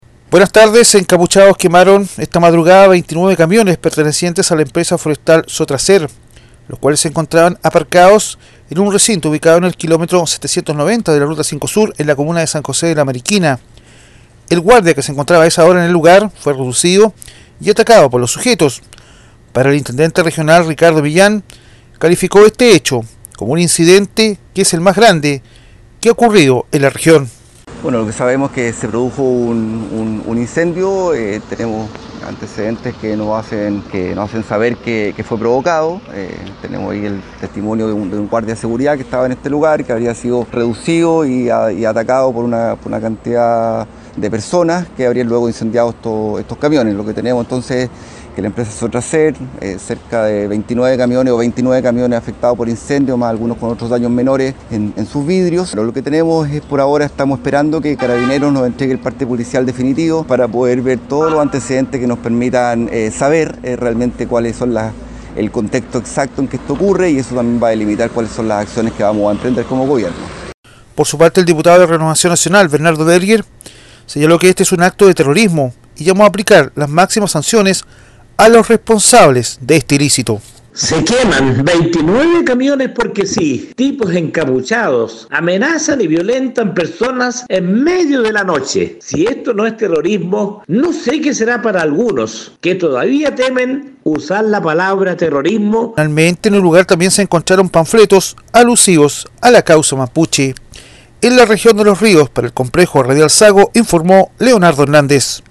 Intendente de Los Ríos se refiere a atentado que afectó a 29 camiones en San José de la Mariquina